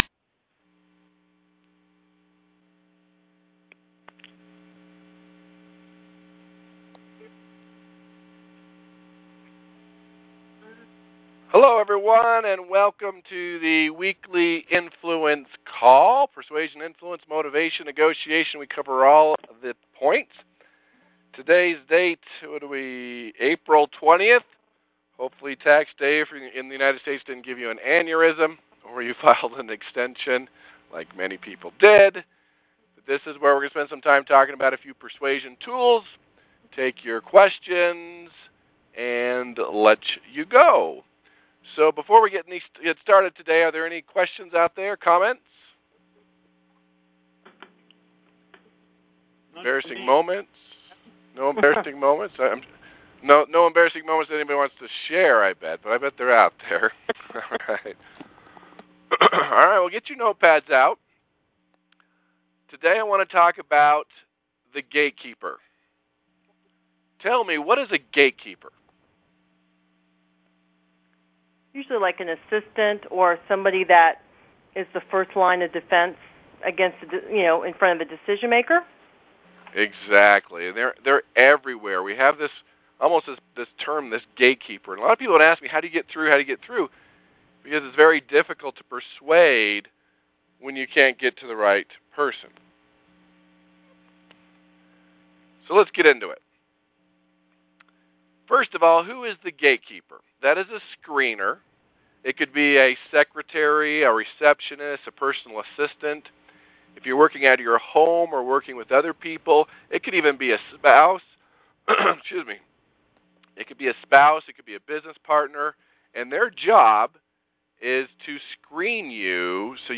‹ Dark tactics Denial and Dissonance › Posted in Conference Calls